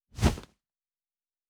Wing Flap 4_4.wav